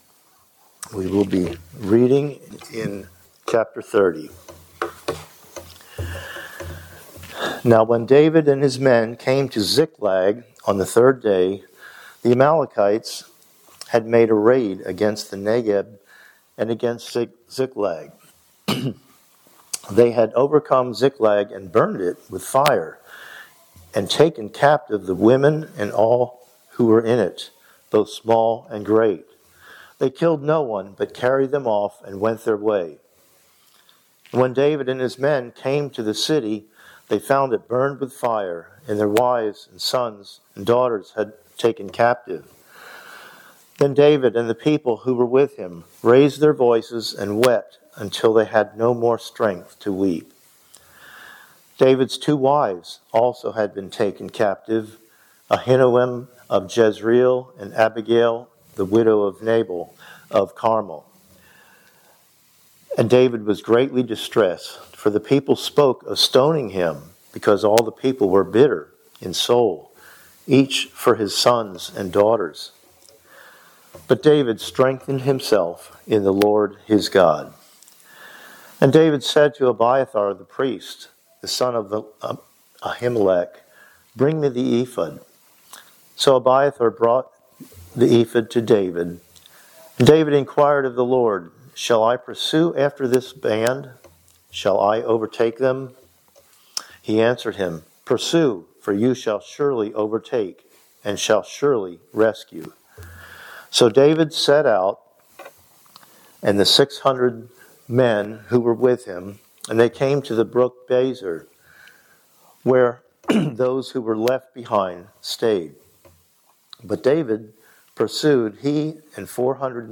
Passage: 1 Samuel 30:1-31 Service Type: Sunday Morning Worship